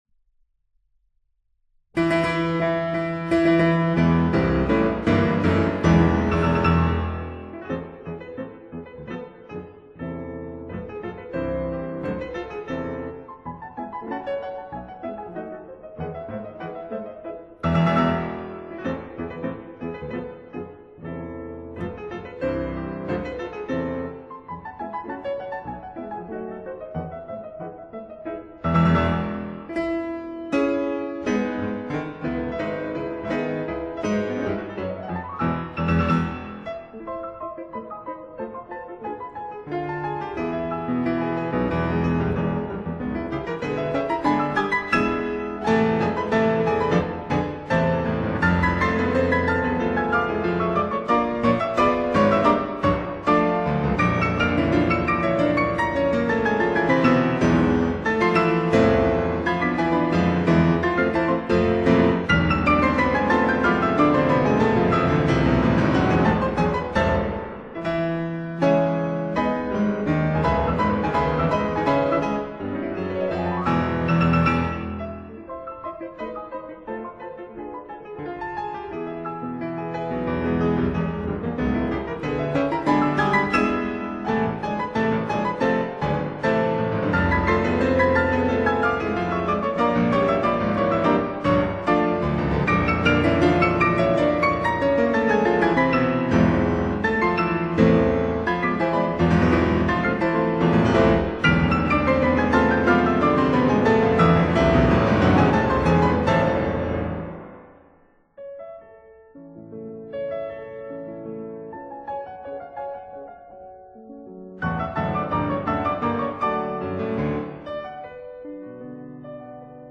Genre: Classical Music, Piano